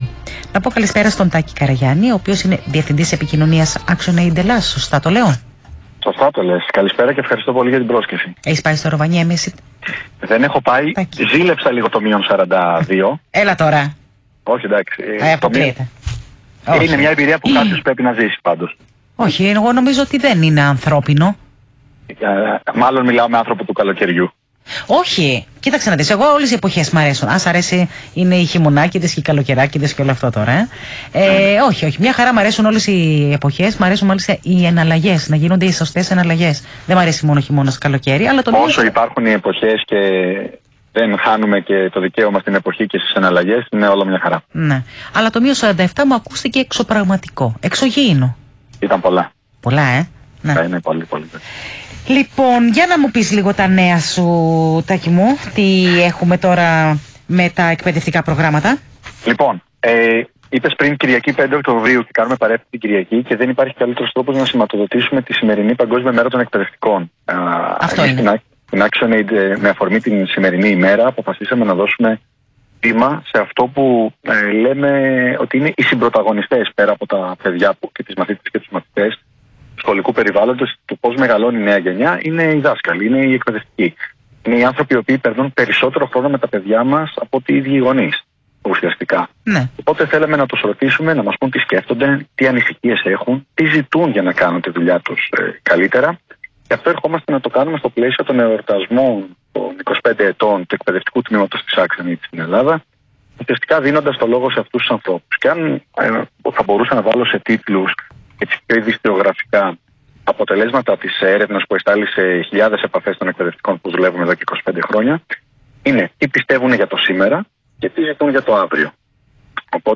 Συνέντευξη στο ραδιόφωνο του ΣΚΑΪ για την Παγκόσμια Ημέρα Εκπαιδευτικών